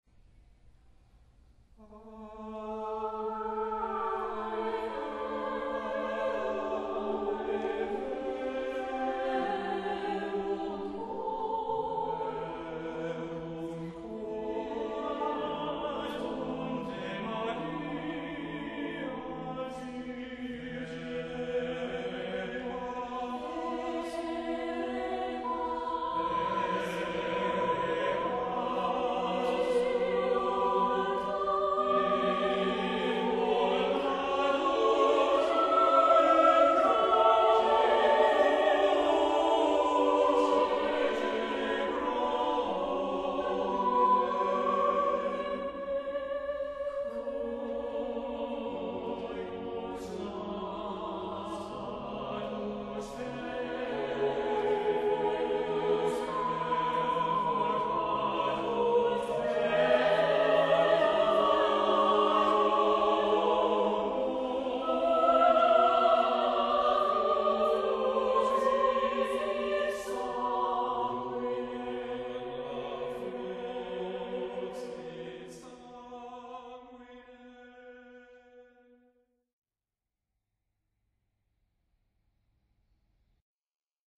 • Music Type: Choral
• Voicing: SATB
• Accompaniment: a cappella
* contemplative, Latin motet